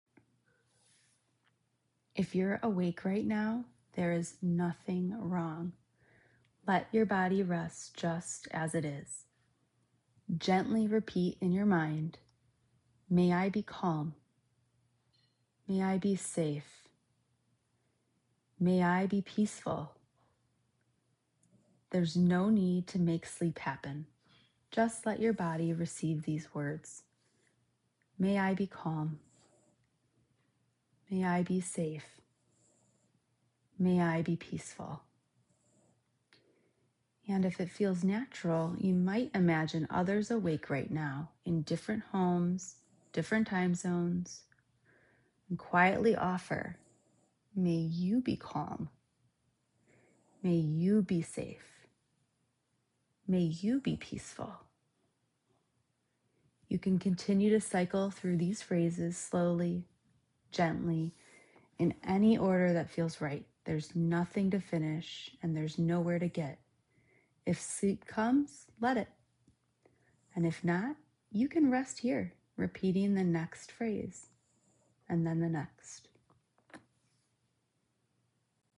One-Minute Nighttime Compassion Meditation
1-Minute-Nighttime-Compassion-Meditation.m4a